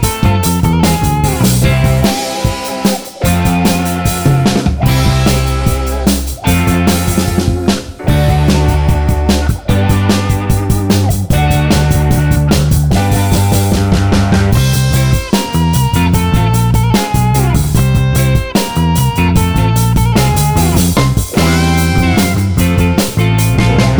Live Country (Male) 2:26 Buy £1.50